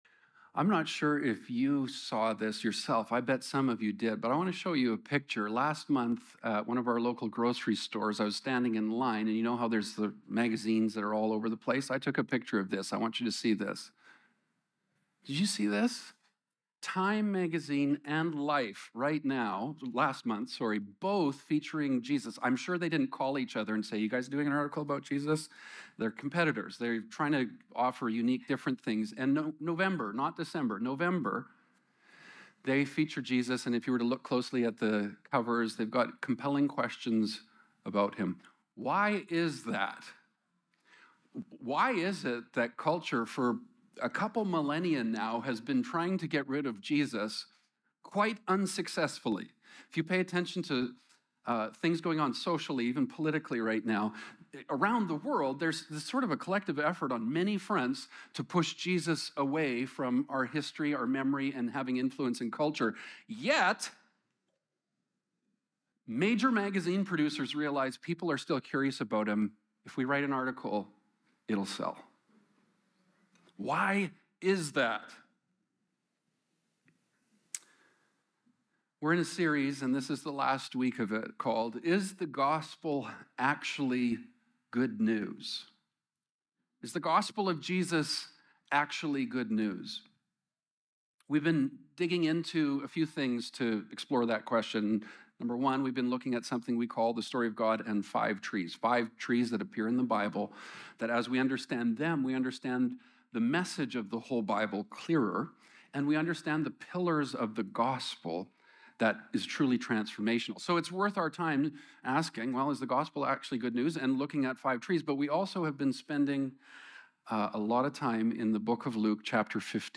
Current Sermon Is The Gospel Actually Good News?